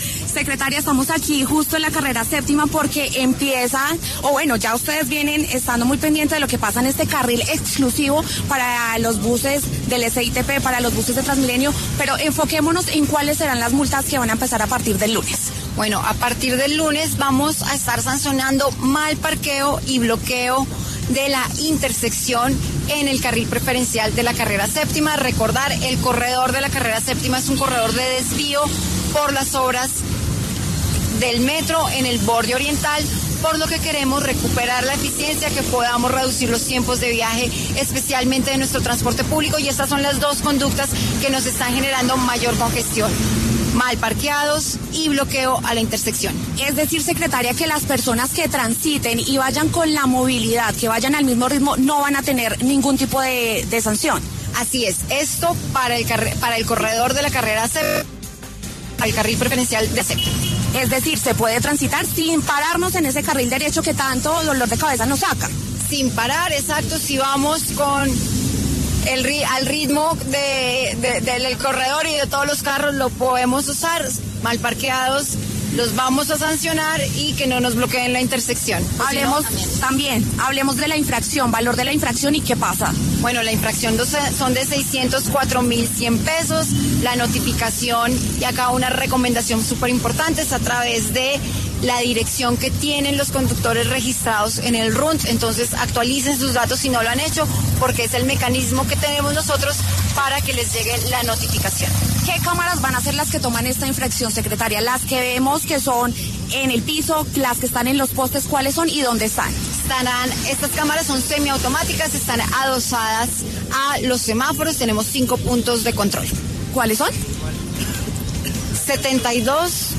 Claudia Díaz, secretaria de Movilidad de Bogotá, habló en La W, con Julio Sánchez Cristo, a propósito de las multas que tendrán los conductores que estén mal parqueados en el carril exclusivo en la Carrera Séptima.